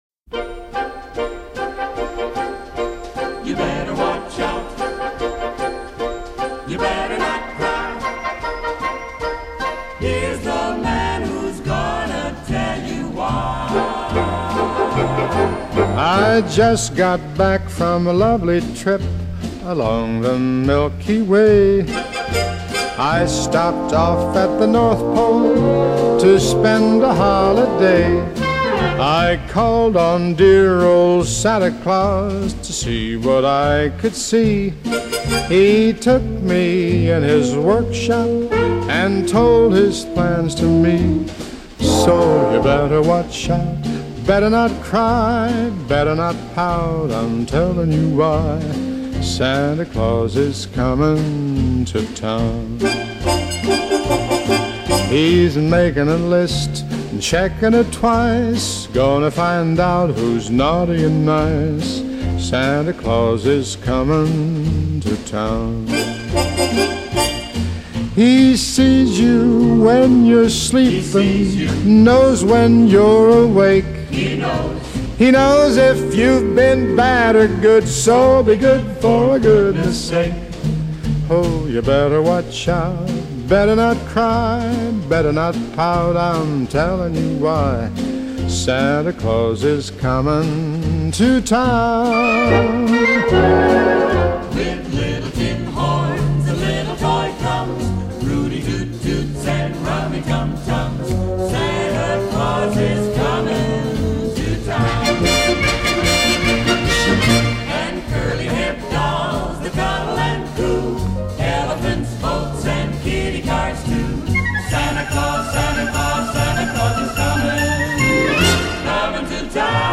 类　　别: Christmas, Pop　　　　　　　　　　  　　.